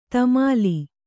♪ tamāli